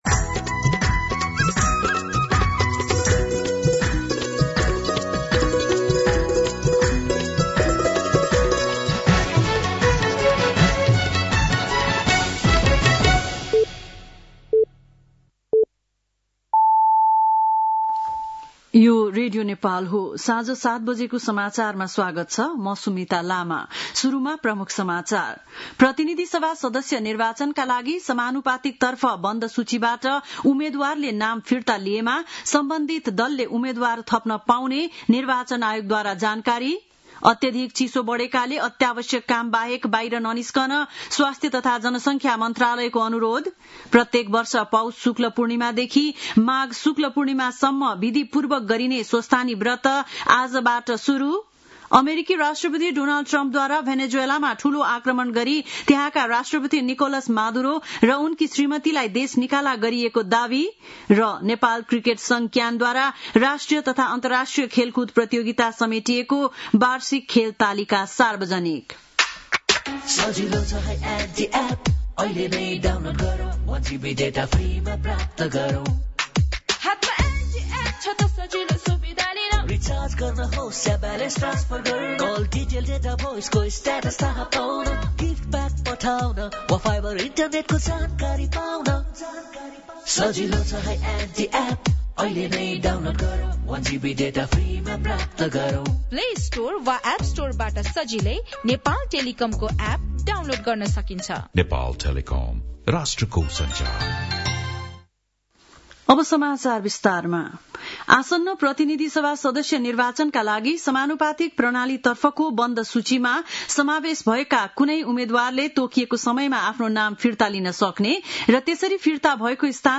बेलुकी ७ बजेको नेपाली समाचार : १९ पुष , २०८२